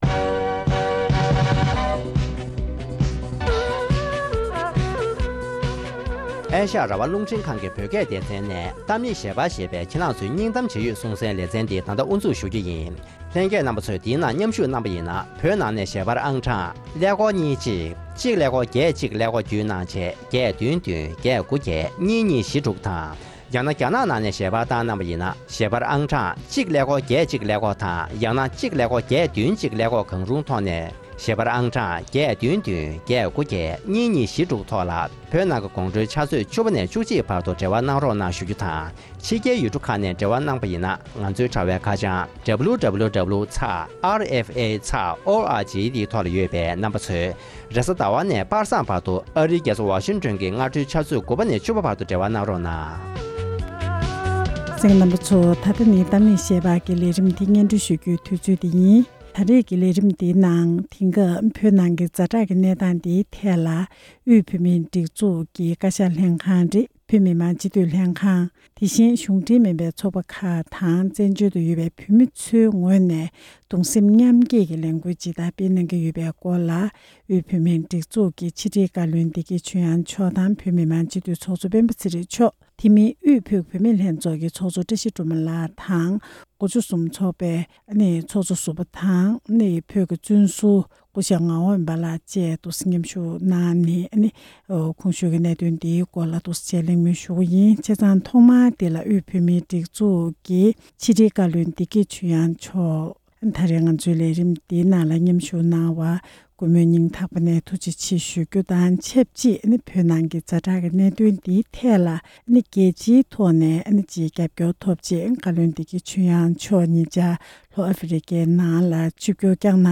བོད་ཀྱི་ད་ལྟའི་ཛ་དྲག་གནས་སྟངས་སྐོར་བོད་མི་མང་སྤྱི་འཐུས་ཚོགས་གཙོ་དང་། ཕྱི་དྲིལ་བཀའ་བློན་གྱི་དབུས་པའི་གཞུང་འབྲེལ་མ་ཡིན་པའི་ཚོགས་པ་ཁག་ཅིག་གི་གནས་ཡོད་མི་སྣར་གླེང་མོལ་ཞུས་པའི་ལས་རིམ།